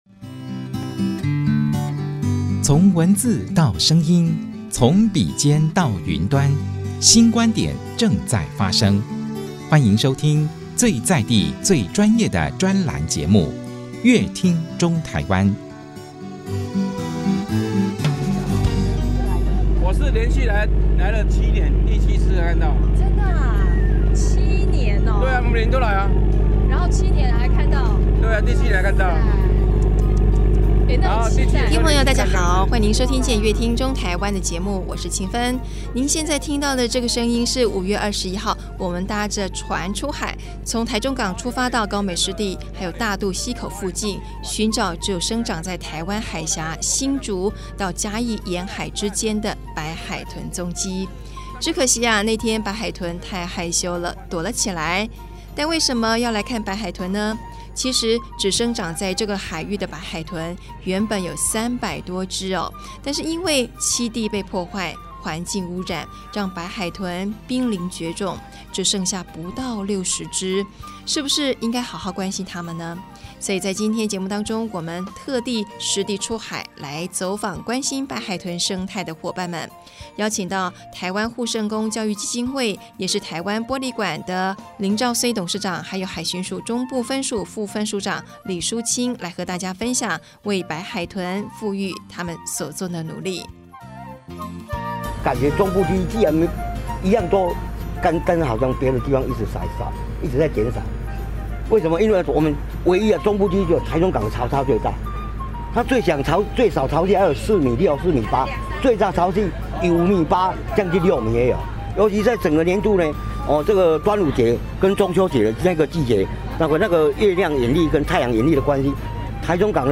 剛出生是灰色，長大是白色，快速游動時又會轉變成粉紅色，牠是台灣海域特有生物「白海豚」，為了一探牠的廬山真面目，一大清早我們搭著船出海，從台中港出發在高美溼地及大肚溪口附近，只為尋找生長在台灣海峽新竹到嘉義沿海間的白海豚，只可惜那天白海豚太害羞了，躲了起來。